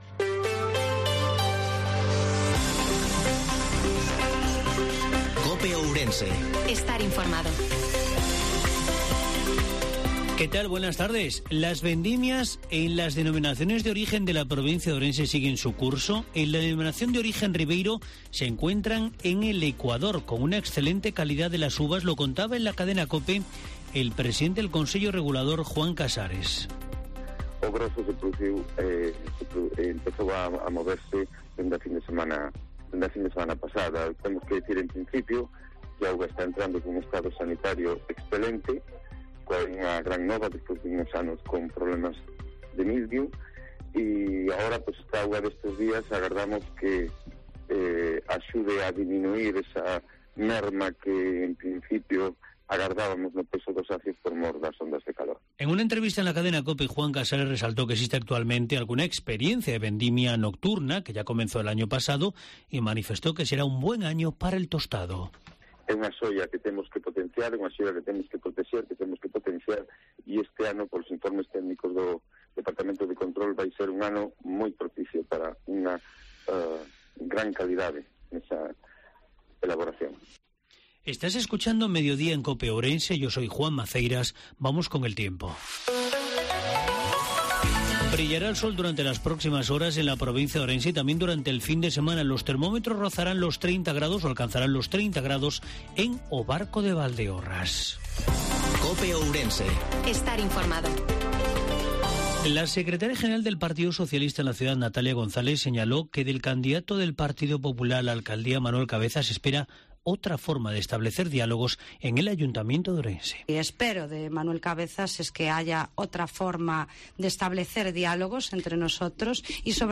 INFORMATIVO MEDIODIA COPE OURENSE-16/09/2022